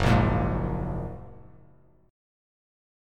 F#add9 chord